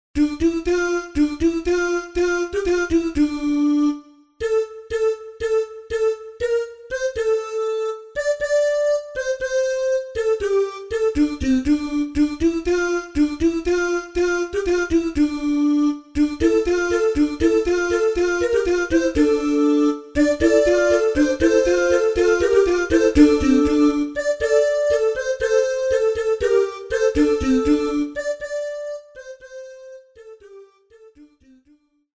RÉPERTOIRE  ENFANTS
CANONS